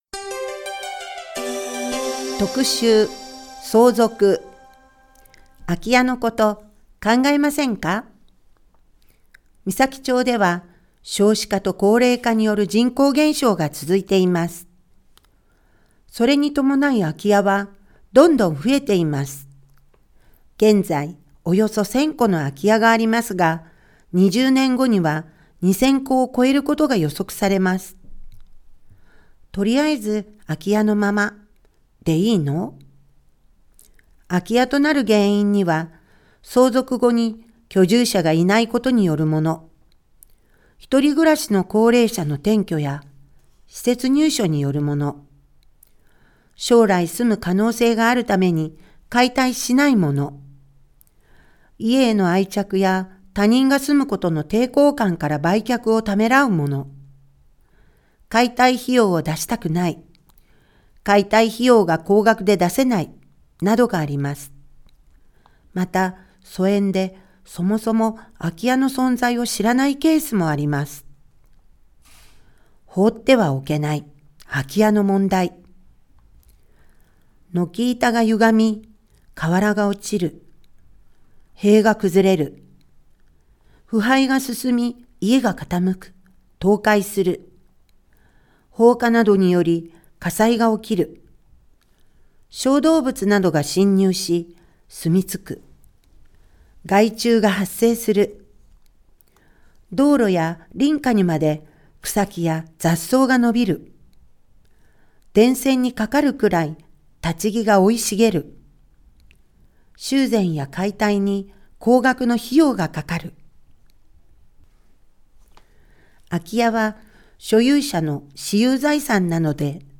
声の広報
広報誌の一部を読み上げています。